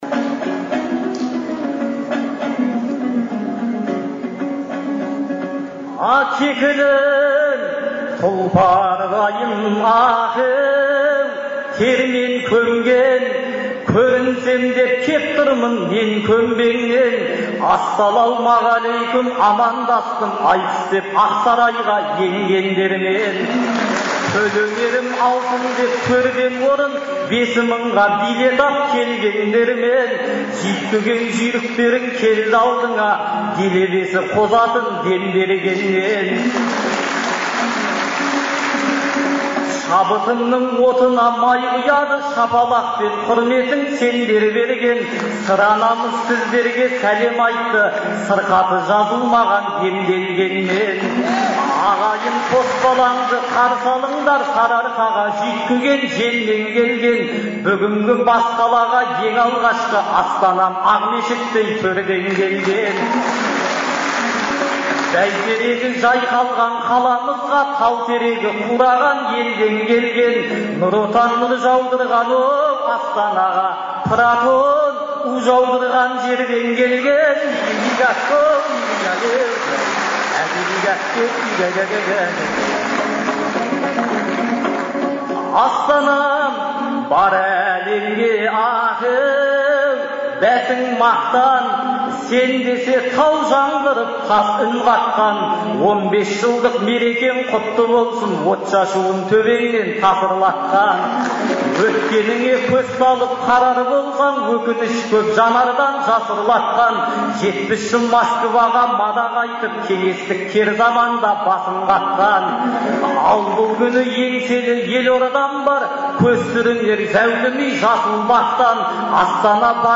Шілденің 8-9-ы күндері Астанада қала күніне орай «Ел, Елбасы, Астана» деген атпен ақындар айтысы өтті.